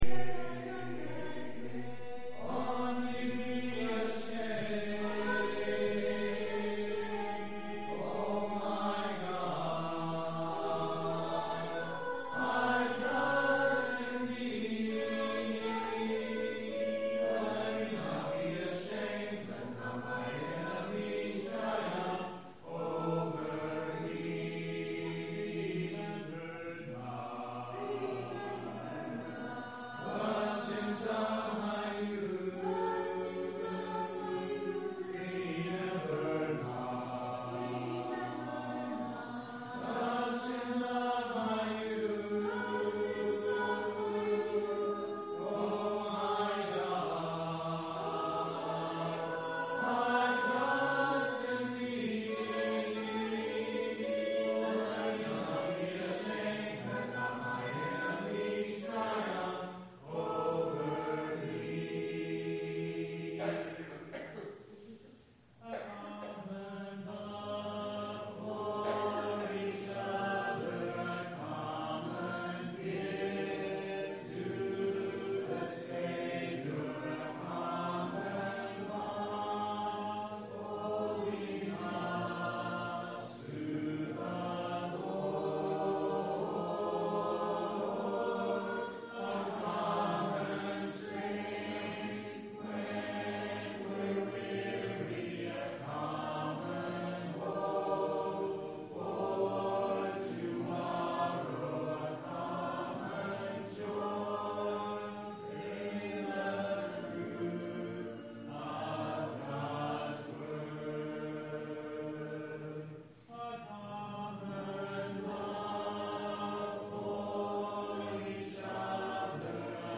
September 2nd – Sermons